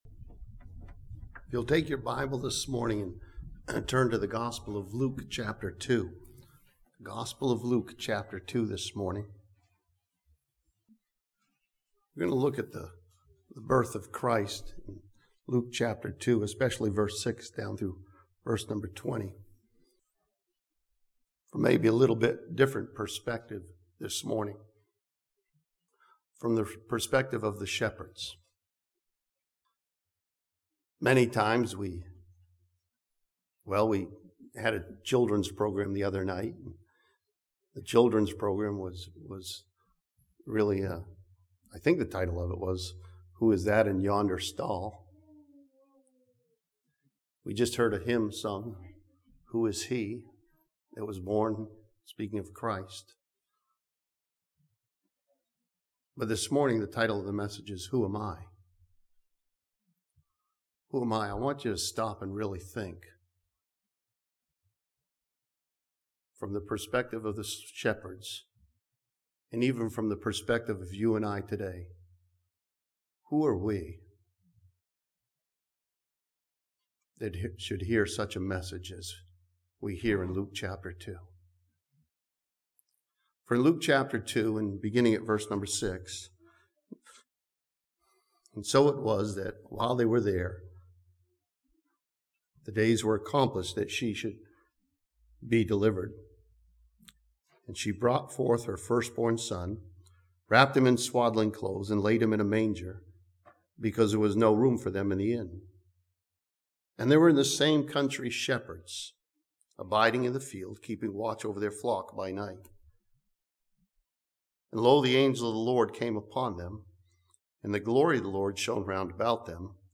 This sermon from Luke chapter 2 studies the shepherds at the birth of Christ and ask the question "who am I?"